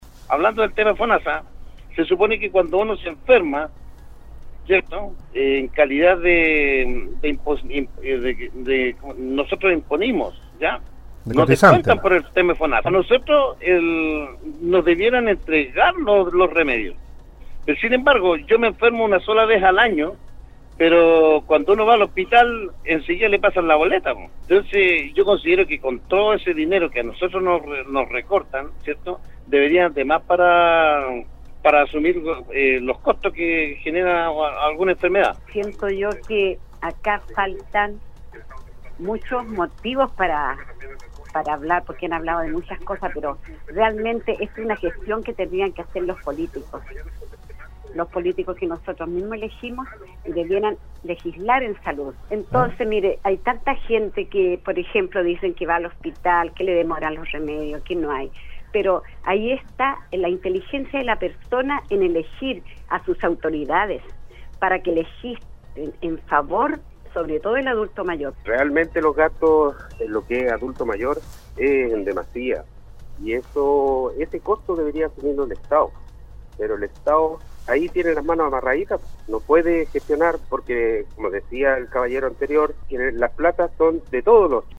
En relación a esto, se recibieron llamadas y mensajes, que en su mayoría, destacan el alto costo de los medicamentos y sobre todo, que el estado debería de cubrir esos gastos de la salud de cada ciudadano del país.